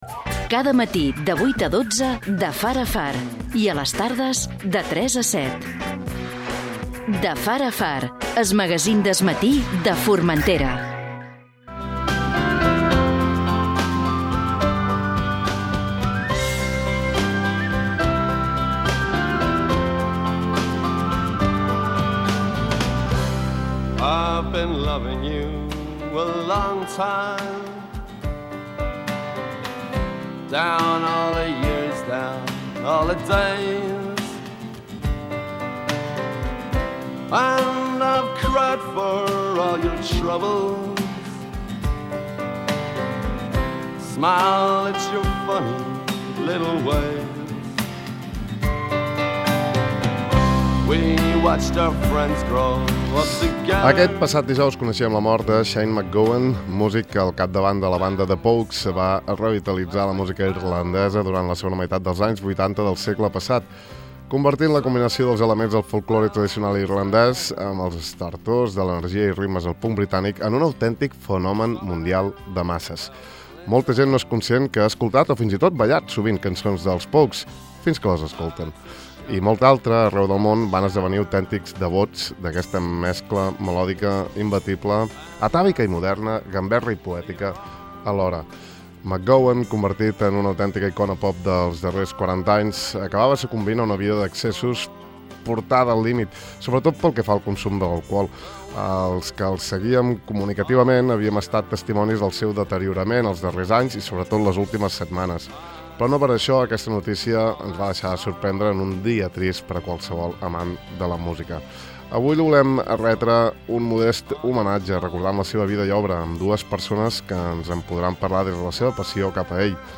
Avui li hem volgut retre un modest homenatge, recordant la seva vida i obra, amb dues persones que ens n’han parlatr des de la seva passió cap a ell.
Avui es troben fora de Formentera i ens han atès via telefònica.